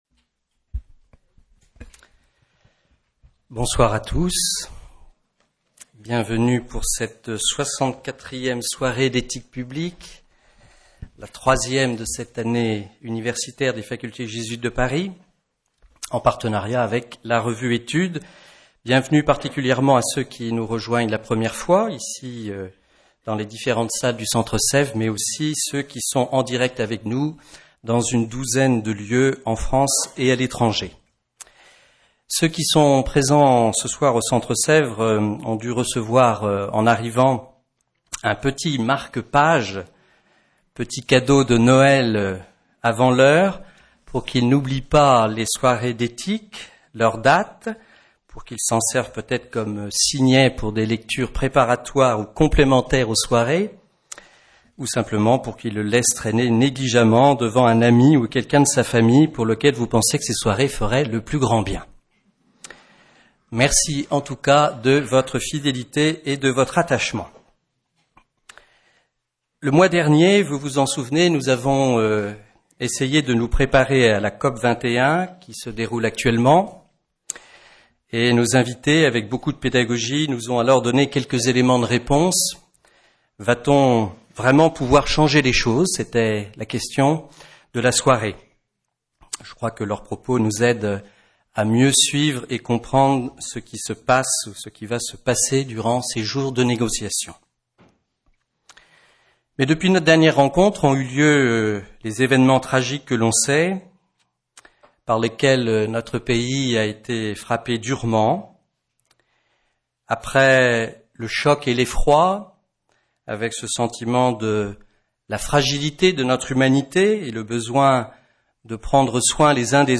Avec la participation de : M. Pavel FISCHER